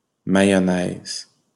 wymowa:
IPA/ma.jɔ.nɛz/